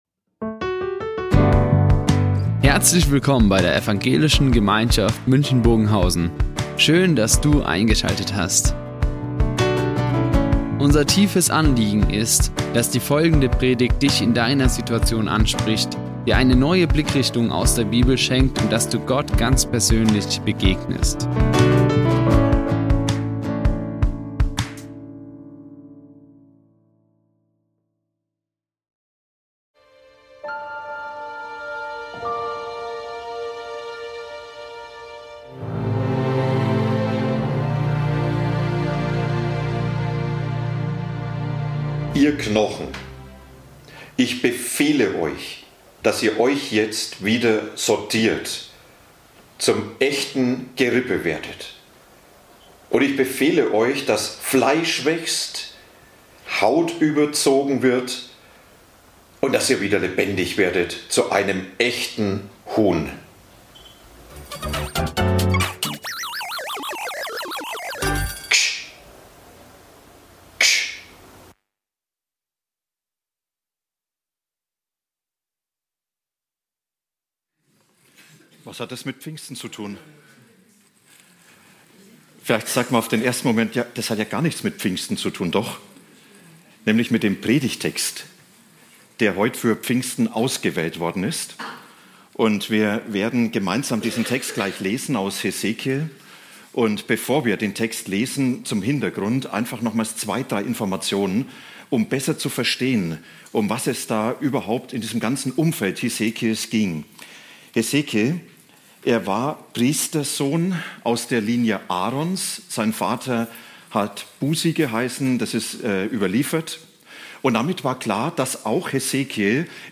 Pfingsten belebt | Predigt Hesekiel 37, 1-14 ~ Ev.
1-14 zum Thema "Pfingsten belebt" Die Aufzeichnung erfolgte im Rahmen eines Livestreams.